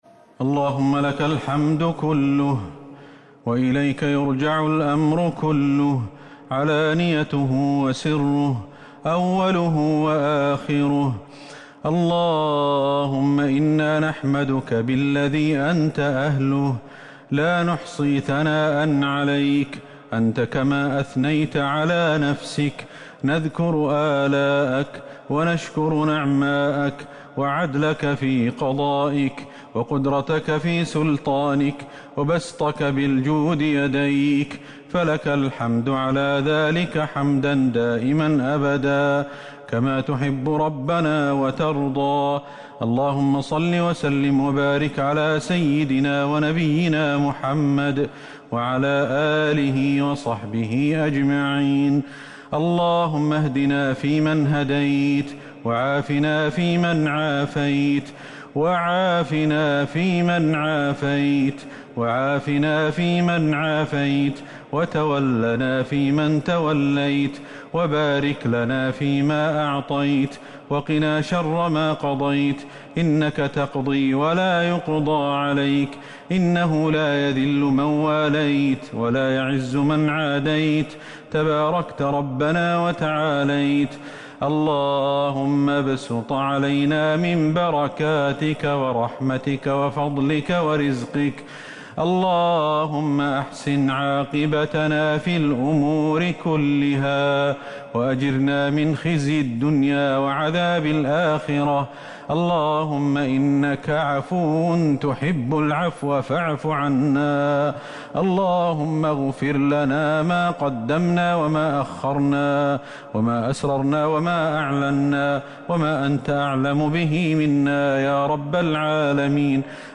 دعاء القنوت ليلة 18 رمضان 1441هـ > تراويح الحرم النبوي عام 1441 🕌 > التراويح - تلاوات الحرمين